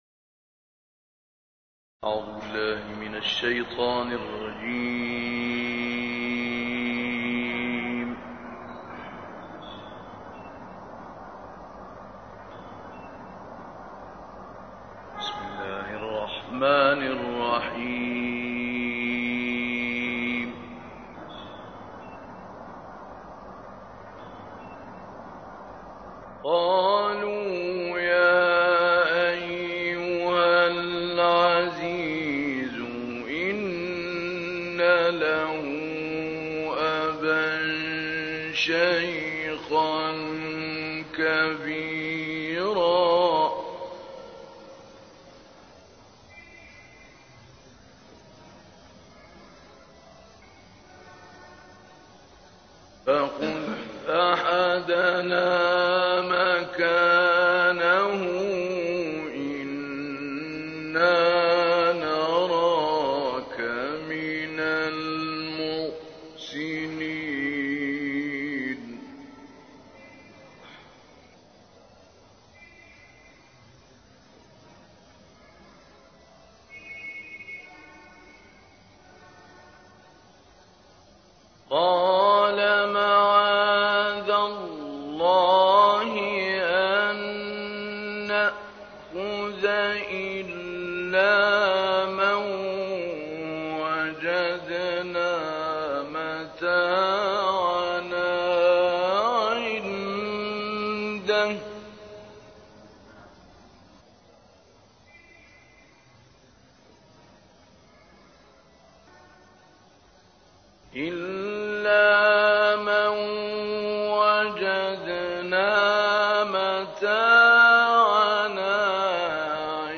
علاوه بر این، تلمّذ او در حوزه موسیقی و تواشیح دینی را که زنگ موسیقایی و قدرت القاء ویژه‌ای برای تلاوت او فراهم آورده است در حصول این استعداد، سهیم فرض می‌کنند.
به گزارش خبرنگار فرهنگی باشگاه خبرنگاران پویا، «شیخ محمود علی‌البناء»، قاری بزرگی است که در سایه عوامل نه چندان مرتبط با منزلت تخصصی فن تلاوت، به جایگاه والایی در عرصه قرائت قرآن کریم نائل آمد.